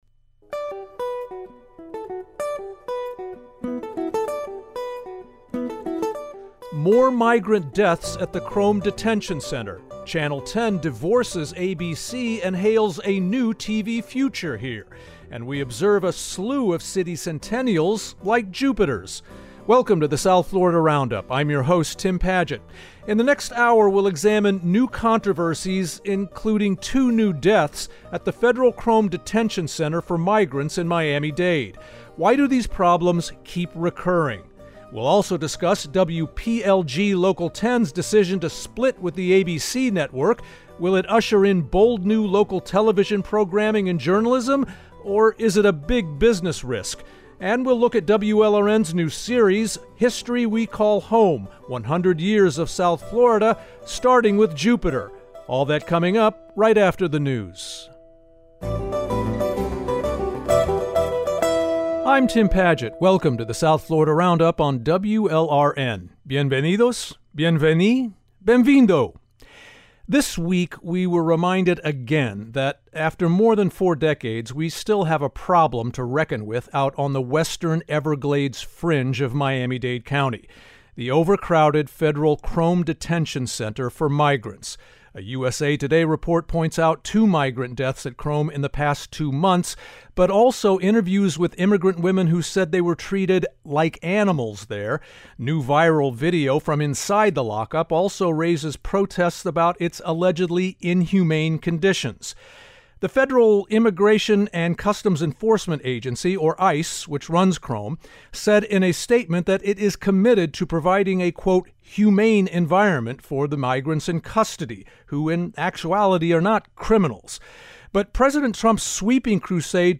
Each week, journalists and newsmakers from South Florida analyze and debate some of the most topical issues from across the region.